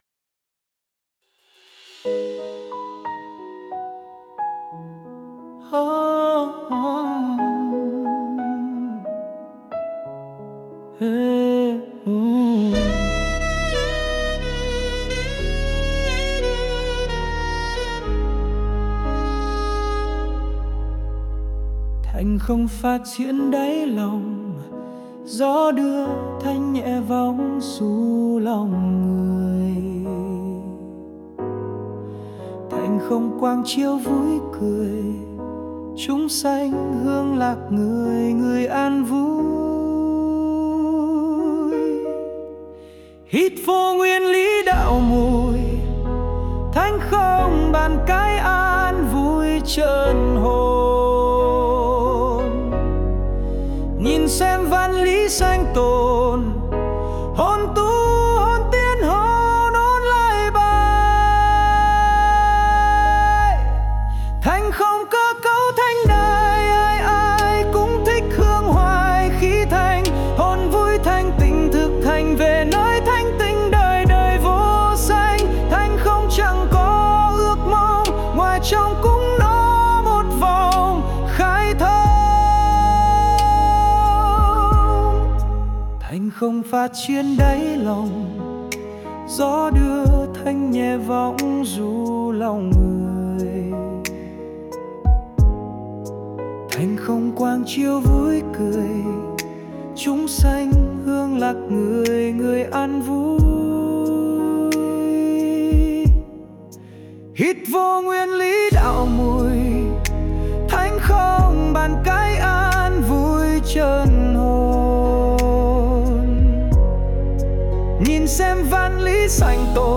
NHẠC THƠ
216-Thanh-khong-02-nam-cao.mp3